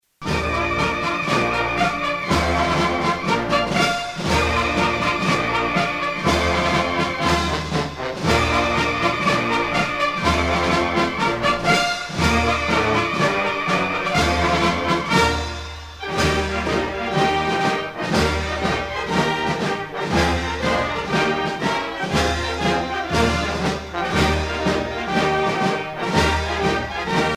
Fonction d'après l'analyste gestuel : à marcher
Usage d'après l'analyste circonstance : militaire
Pièce musicale éditée